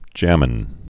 (jămən)